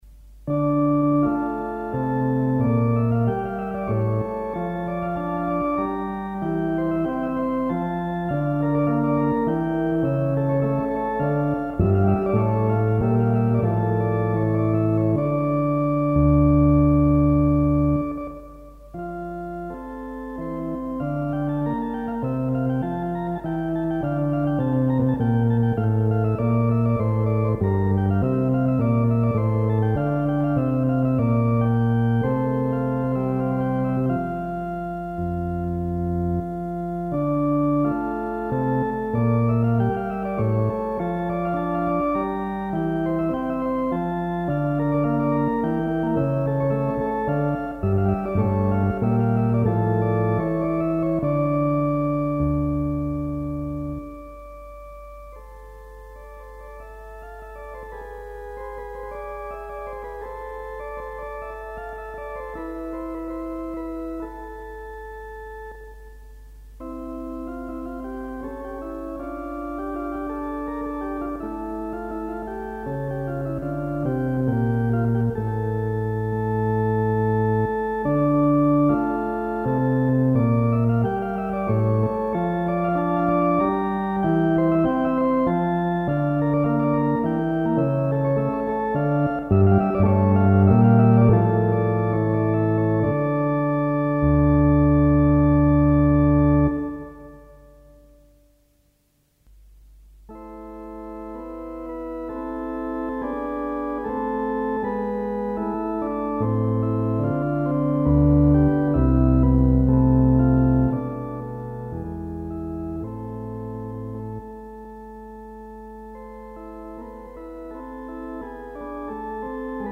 Philicorda
As the recording bug was already well installed (audio only in those days!) a few tape records were made – some of which have survived the intervening years and multiple shifts in technology and media standards.
philicordakh_bach.mp3